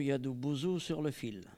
Sallertaine
Catégorie Locution